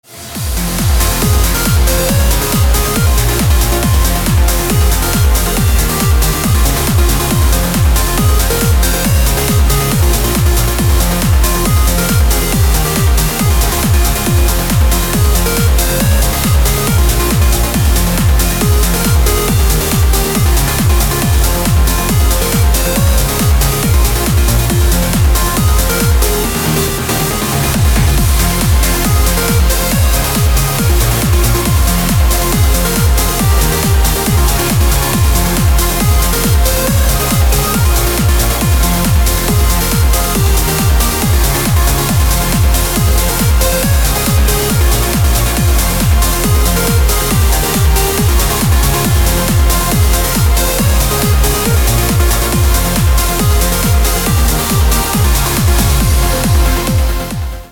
зажигательные
dance
без слов
club